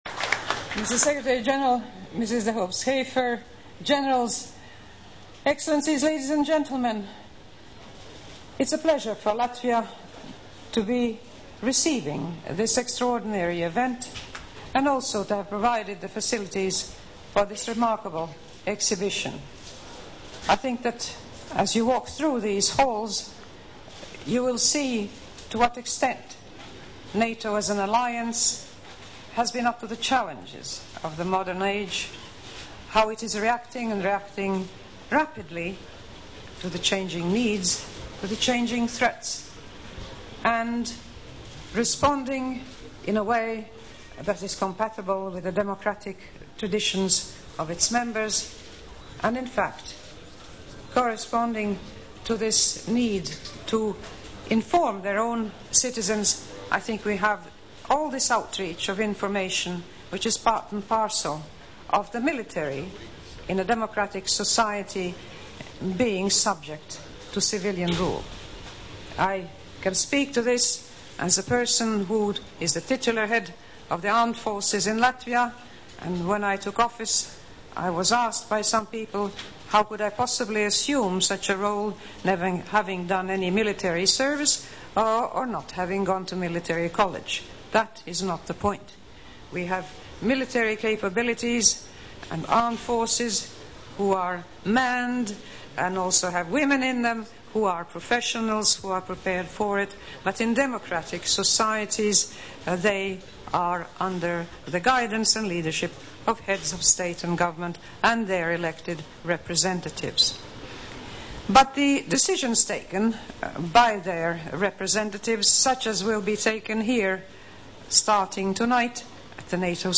Latvijas Valsts prezidentes Vairas Vīķes-Freibergas uzruna NATO galotņu sanāksmes Transformācijas izstādes atklāšanā Olimpiskajā centrā 2006. gada 28. novembrī (+audioieraksts) | Latvijas Valsts prezidenta mājaslapa
Valsts prezidentes uzruna NATO galotņu sanāksmes Transformācijas izstādes atklāšanā